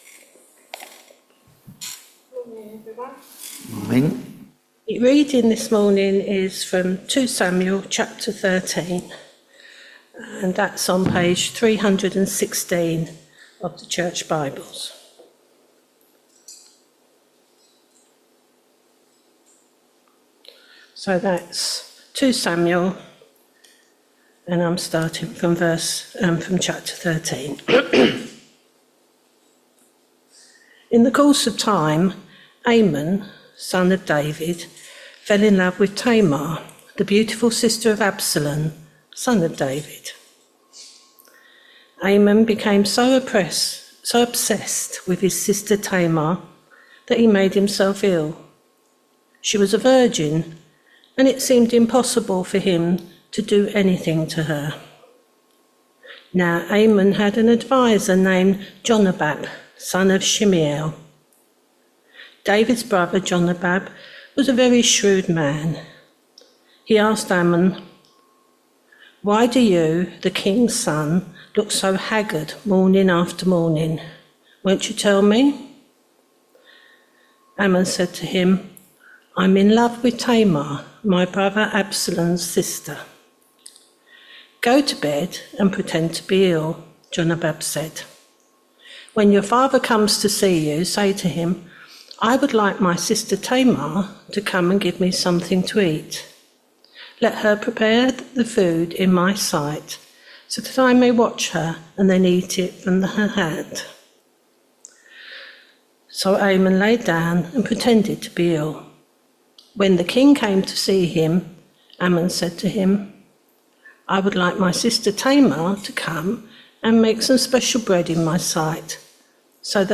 2 Samuel 13vv1-22 Service Type: Sunday Morning Service Topics